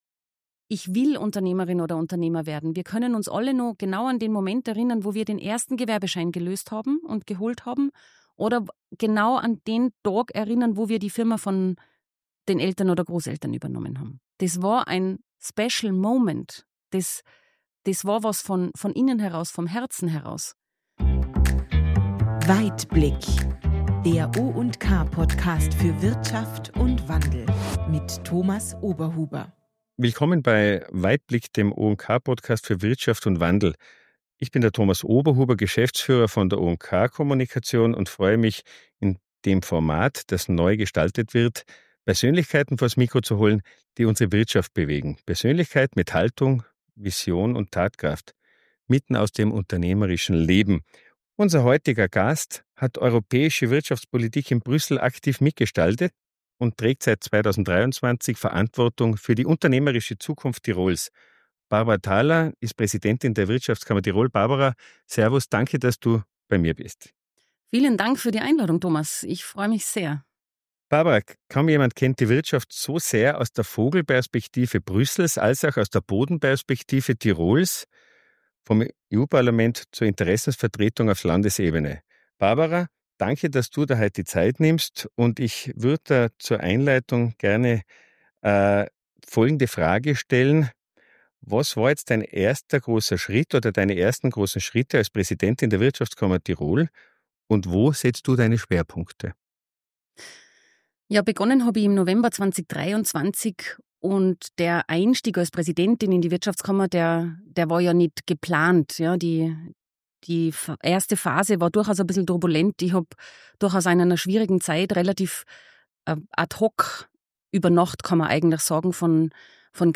Beschreibung vor 5 Monaten Barbara Thaler, Präsidentin der Wirtschaftskammer Tirol und ehemalige EU-Abgeordnete, ist zu Gast in der ersten Folge von Weitblick – Der Podcast für Wirtschaft & Wandel.